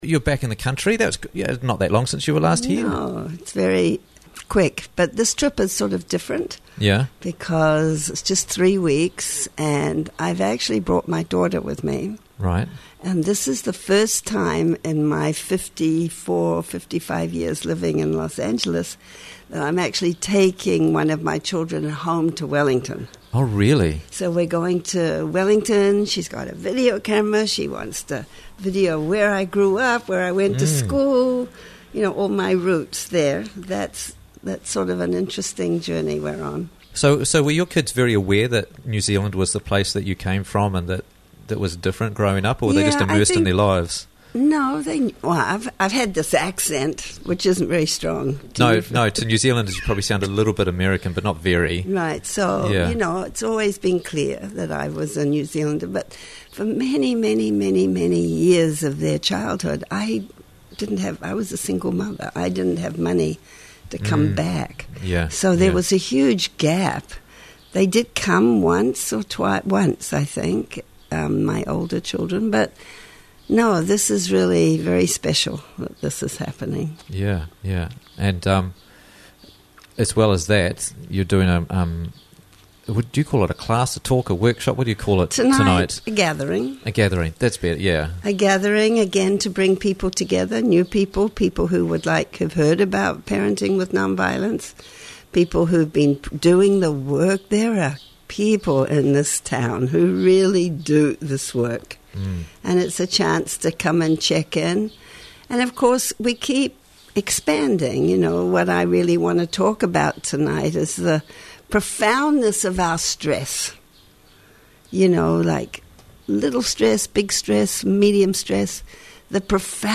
Why Emotional Regulation Is So Important - Interviews from the Raglan Morning Show